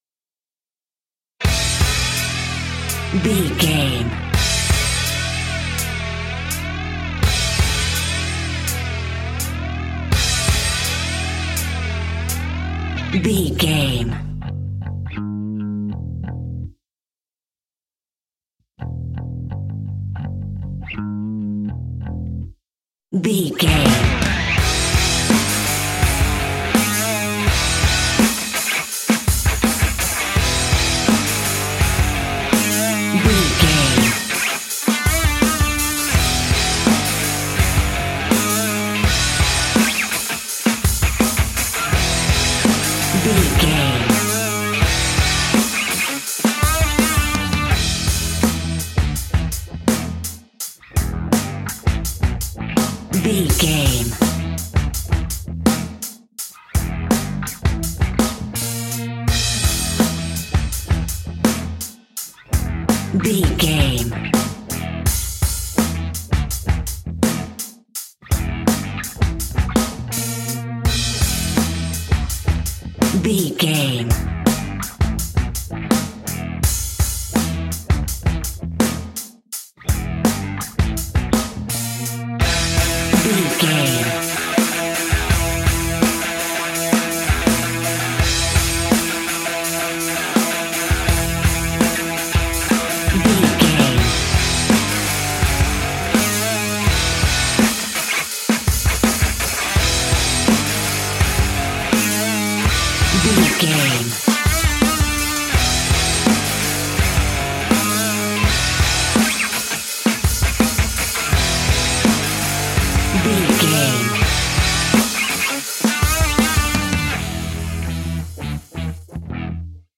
Aeolian/Minor
hard rock
heavy rock
gothic
dirty rock
scary rock
instrumentals
Heavy Metal Guitars
Metal Drums
Heavy Bass Guitars